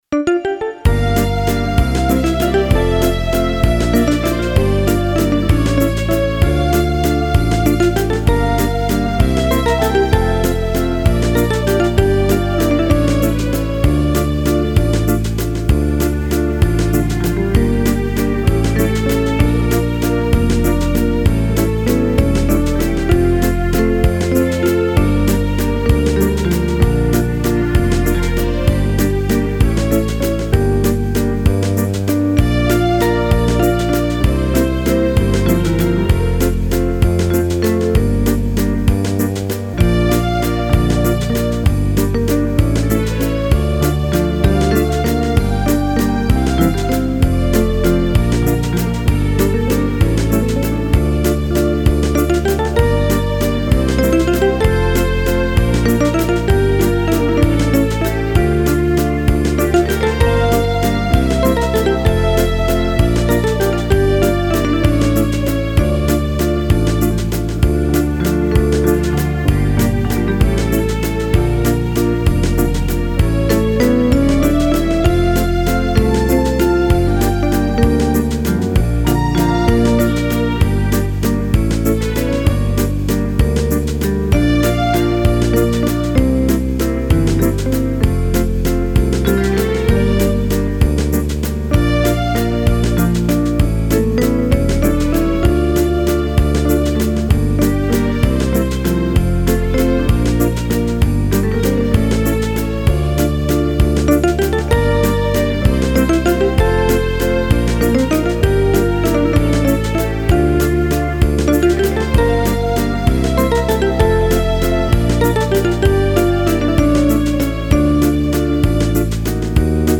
Детские